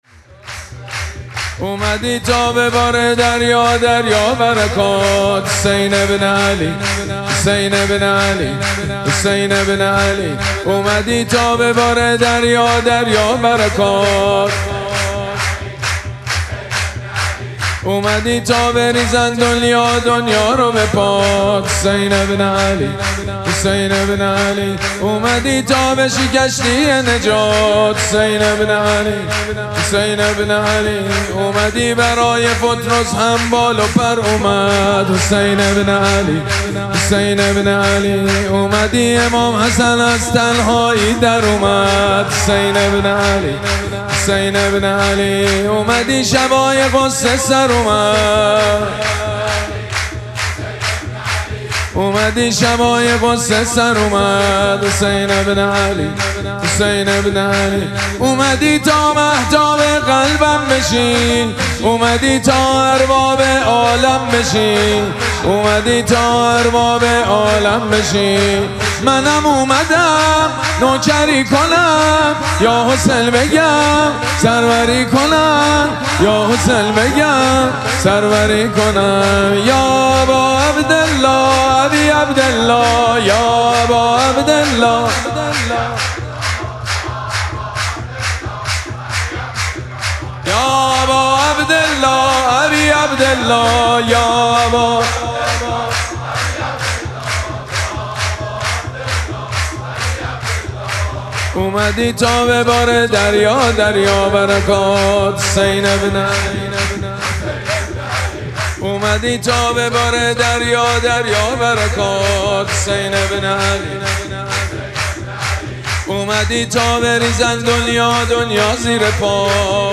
شب اول مراسم جشن ولادت سرداران کربلا
حسینیه ریحانه الحسین سلام الله علیها
سرود
حاج سید مجید بنی فاطمه